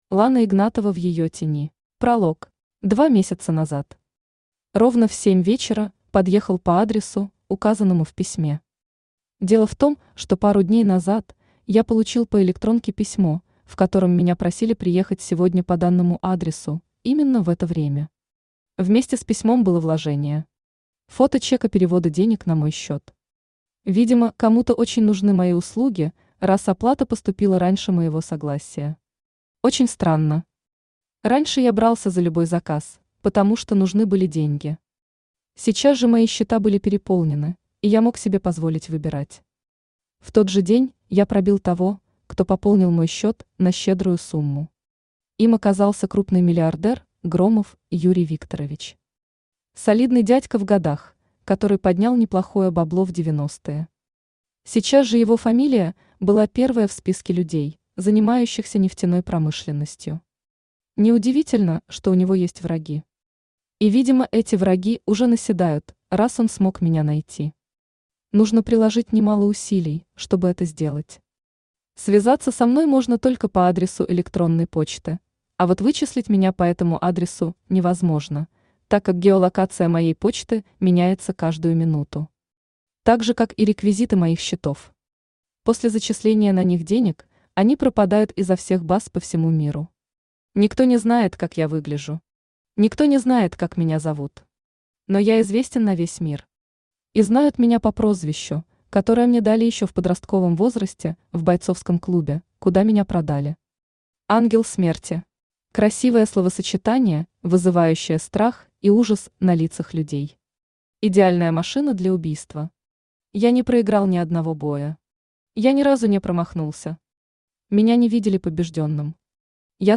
Aудиокнига В её тени Автор Лана Александровна Игнатова Читает аудиокнигу Авточтец ЛитРес.